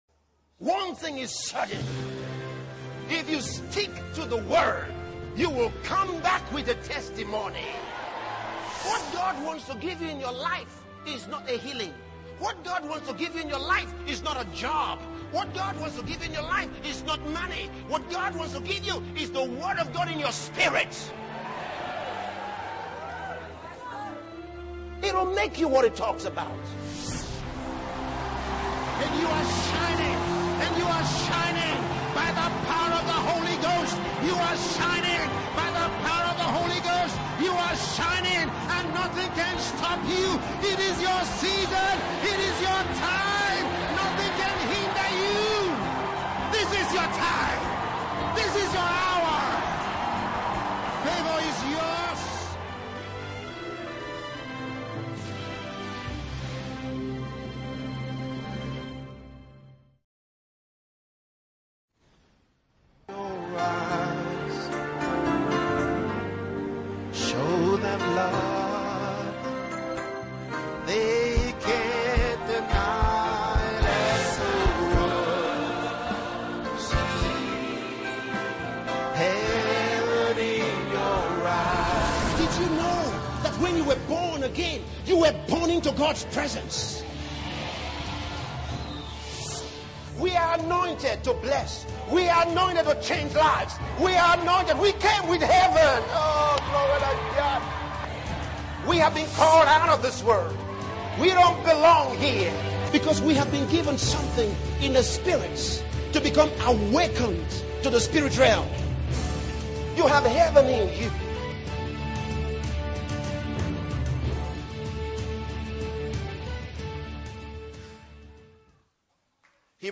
Disc 1 (DOWNLOAD SERMON) Disc 2 (DOWNLOAD SERMON)